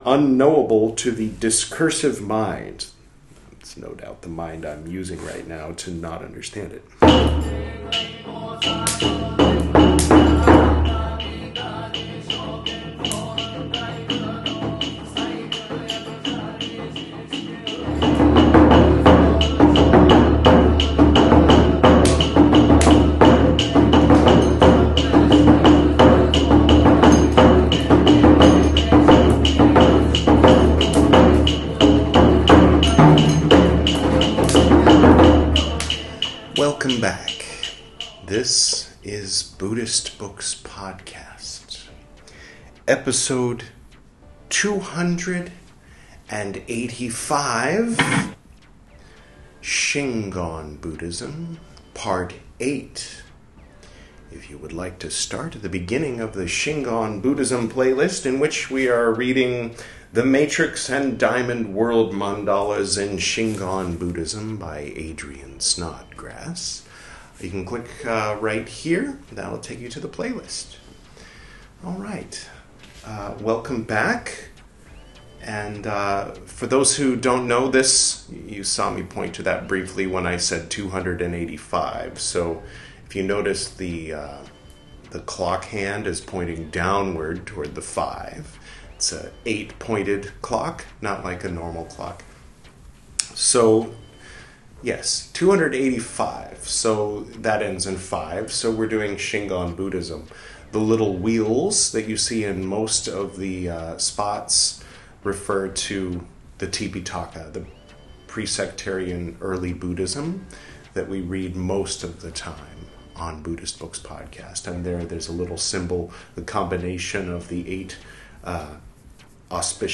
This is Part 8 of my recital of the 'The Matrix and Diamond World Mandalas in Shingon Buddhism' by Adrian Snodgrass. Shingon Buddhism is, put simply, the Japanese version of Vajrayana Buddhism.